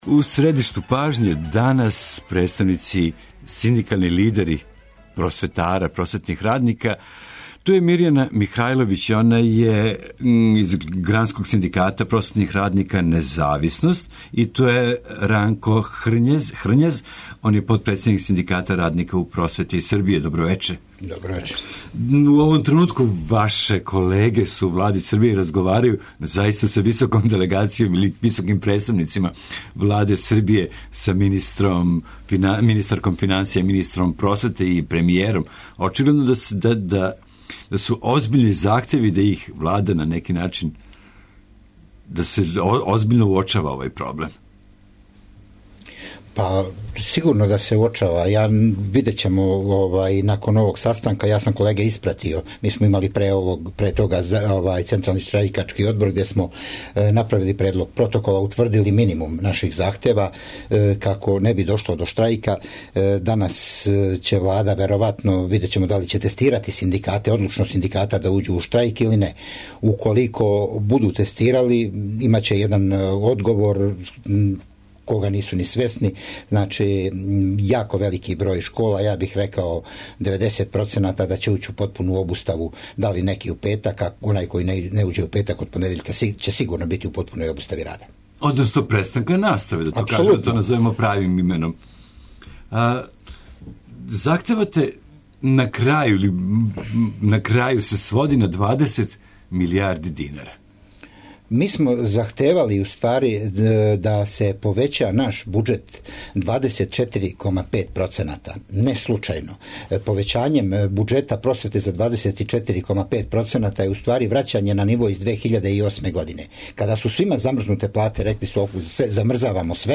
У емисији, такође, емитујемо и ексклузивни интервју који је Радио Београду 1 дао специјални известилац Савета Европе Дик Марти.